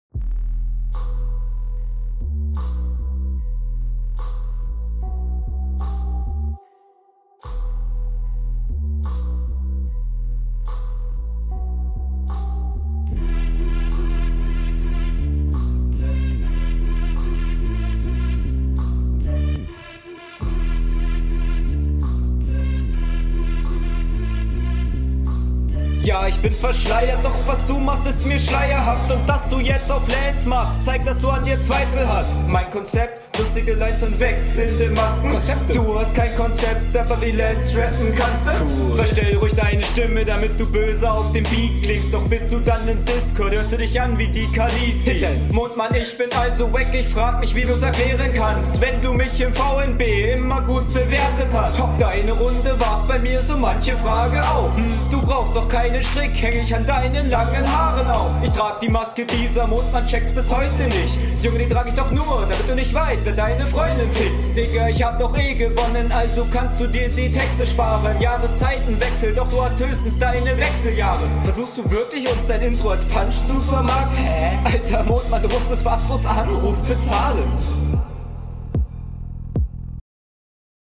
Audiotechnisch etwas schlechter und ein paar Flows klingen unroutiniert.
Ja also Flow deutlich schwächer, aber eigener. Du klingst dünner und unsicherer.